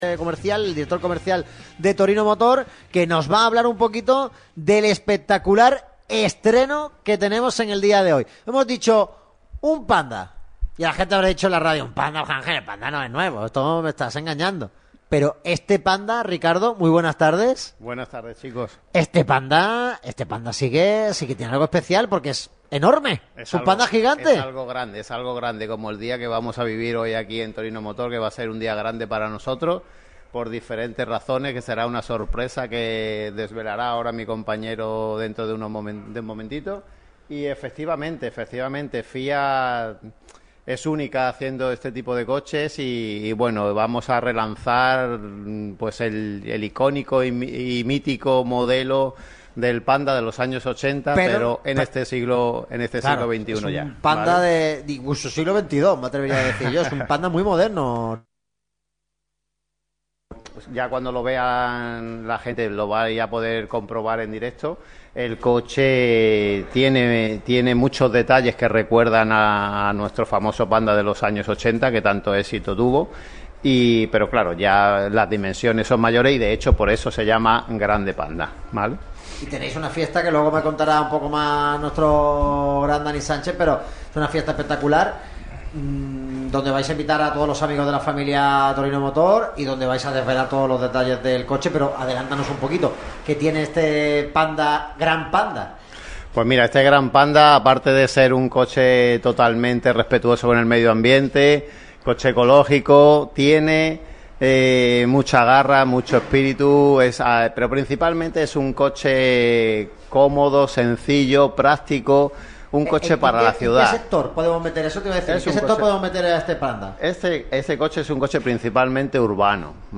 Radio MARCA Málaga se ha desplazado hasta las instalaciones de Torino Motor, concesionario oficial de Alfa Romeo, Jeep, Fiat, Abarth y Lancia en Málaga, con sede en la calle Cuevas Bajas, 11, en el Polígono Los Chopos, en la zona de la Azucarera, junto al centro comercial Mare Nostrum.